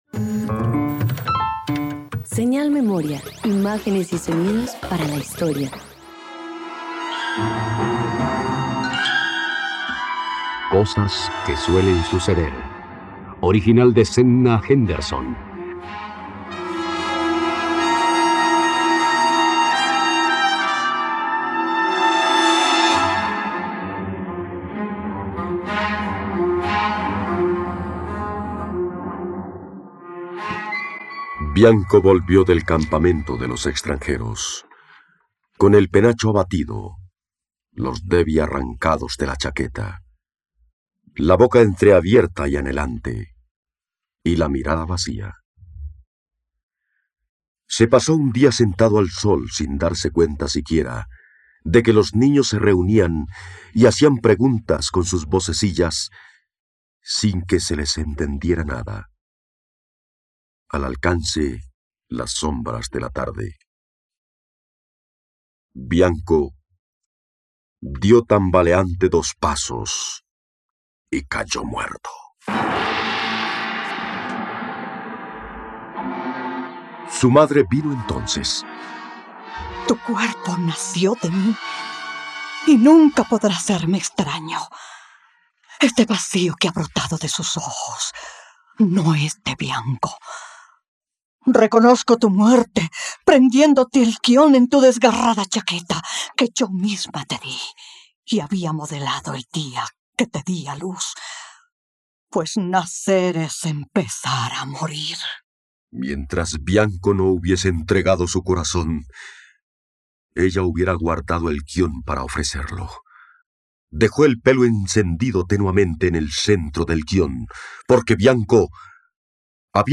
..Radioteatro. Escucha la adaptación radiofónica de “Cosas que deben suceder” de Zenna Henderson por la plataforma streaming RTVCPlay.